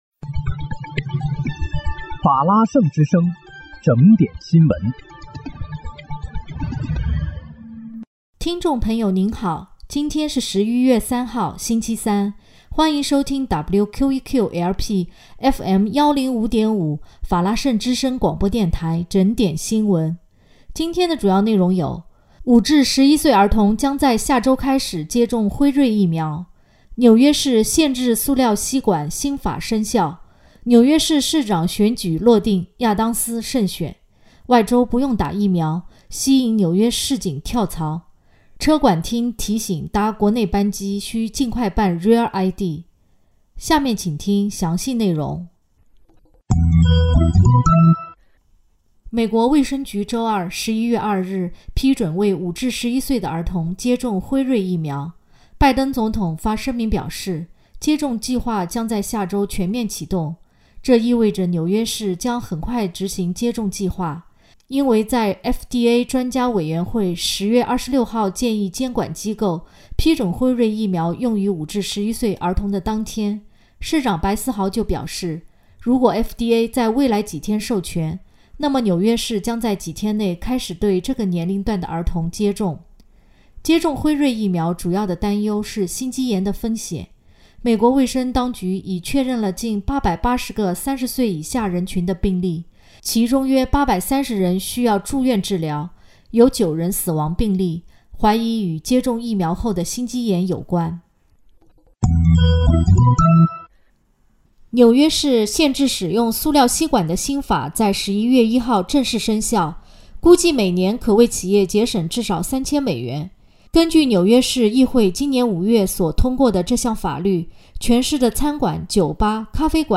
11月3日（星期三）纽约整点新闻